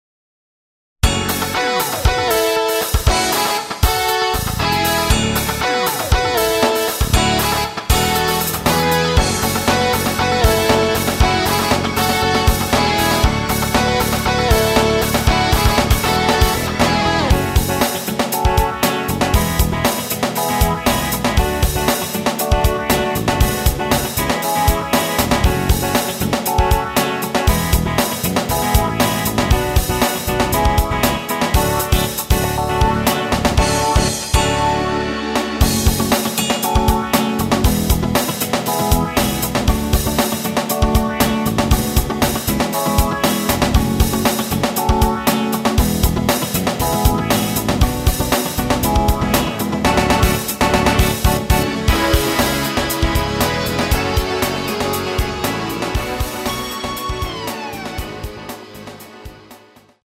Gbm
◈ 곡명 옆 (-1)은 반음 내림, (+1)은 반음 올림 입니다.
앞부분30초, 뒷부분30초씩 편집해서 올려 드리고 있습니다.
중간에 음이 끈어지고 다시 나오는 이유는